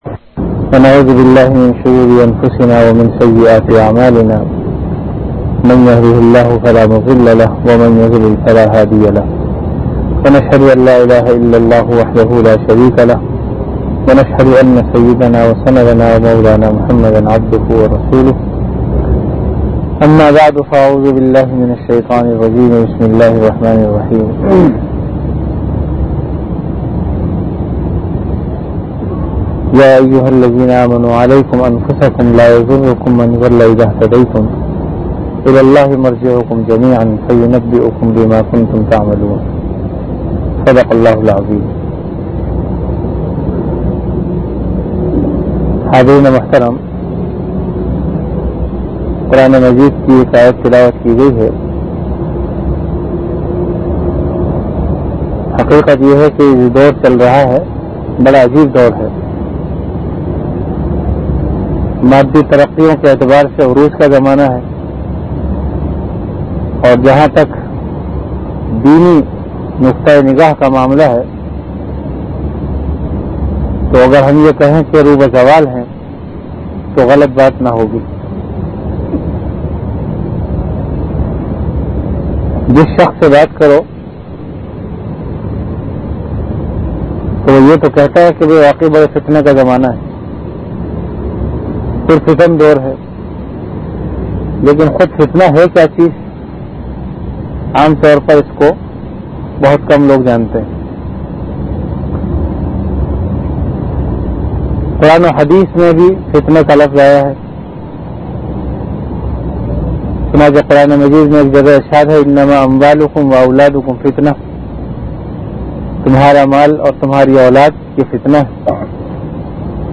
Bayanat · Jamia Masjid Bait-ul-Mukkaram, Karachi
After Isha Prayer